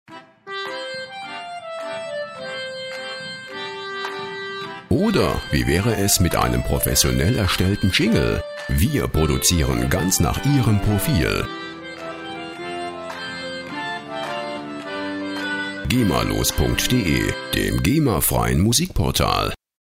Der Klang der Musikinstrumente
Instrument: Melodika
Tempo: 106 bpm